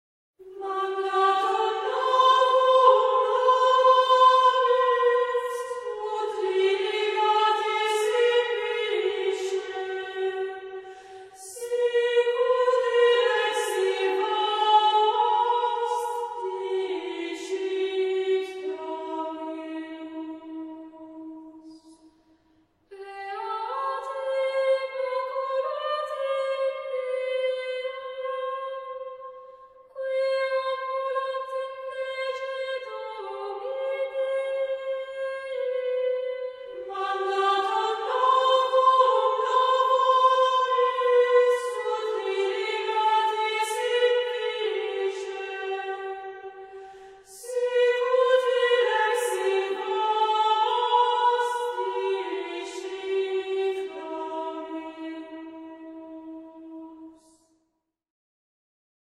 Григорианские песнопения Великого Поста
Песнопения Страстной Недели ("Pueri hebraeorum", "Ubi caritas", "Ecce lignum cruces", "Tenebrae factae sunt", "Pange lingua gloriosi" и др.) в исполнении ансамбля средневековой музыки "Stirps Iesse".
Запись сделана в Коллегии Кастильоне-Олона (Италия) в ноябре 1994 г.